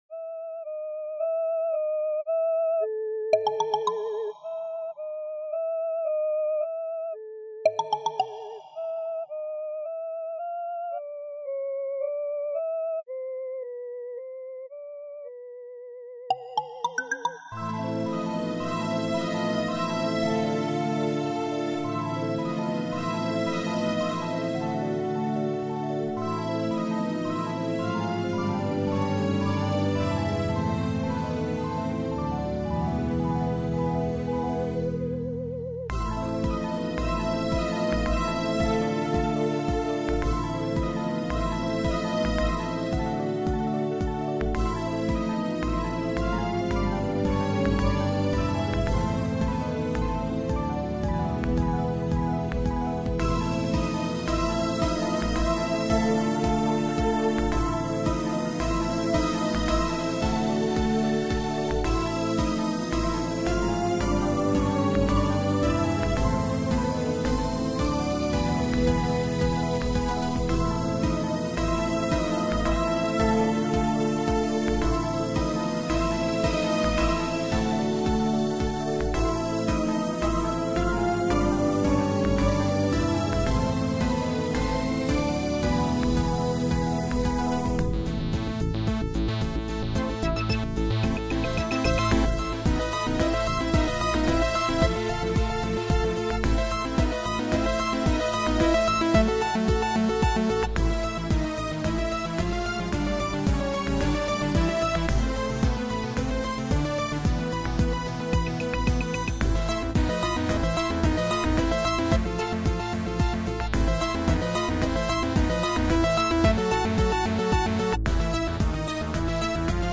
So far it's just an instrumental, later I'd like to add some vocals. The music is a remix of Saint-Saens' Aquarium, and I've made it with Fruity Loops/ Adobe Soundbooth.